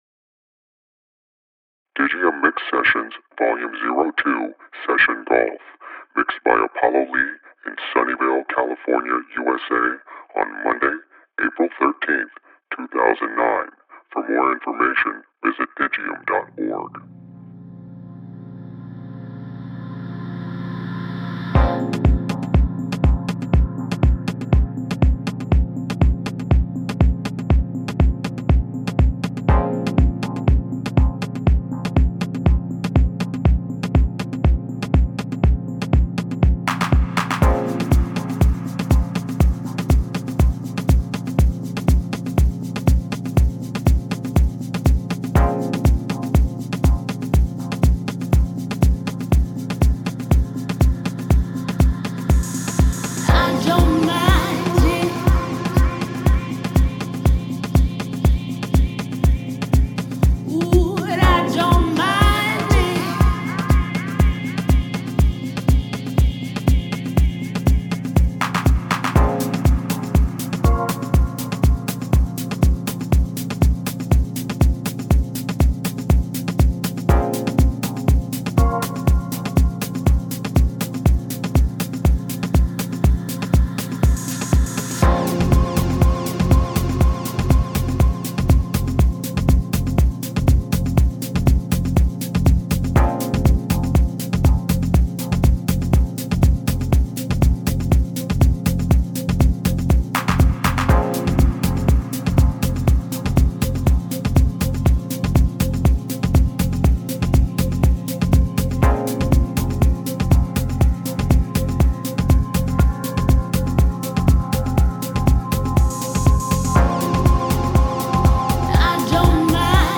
House